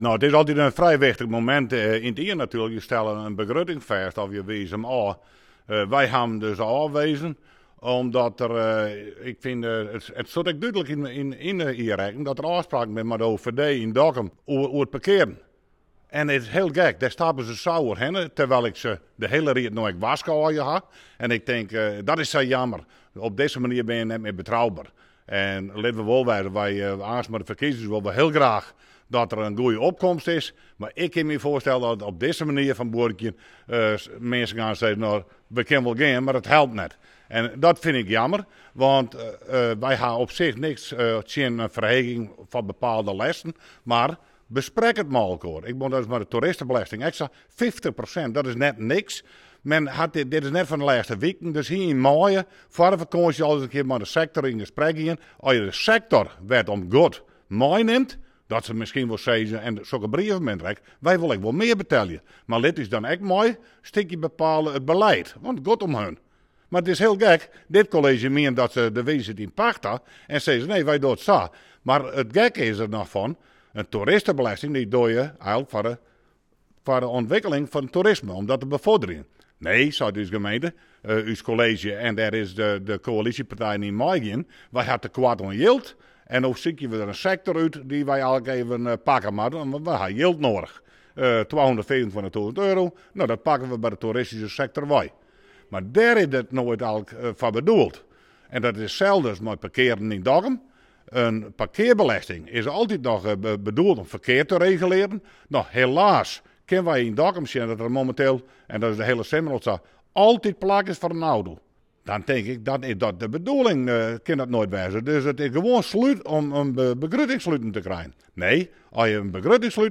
Fractievoorzitter Pieter Braaksma van gemeentebelangen Noardeast-Fryslân over de begroting